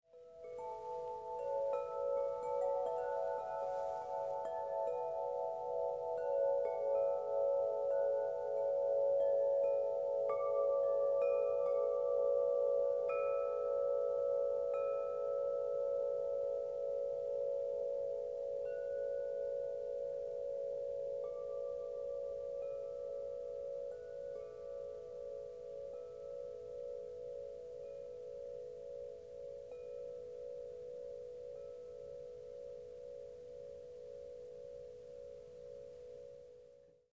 Diese Kollektion kombiniert hochverdichtete Polymer-Schläge mit dickwandigen, pulverbeschichteten Rohren - für eine überragende Resonanz und Elastizität.
Die Verwendung von zentral gehängten Röhren verleiht unseren Klangspielen eine wunderschöne, volle Resonanz und Lautstärke und einen kristallklaren Klang.
Ein weiches Material verleiht jedem Windglockenspiel einen sanften, beruhigenden Nachhall.
Skala C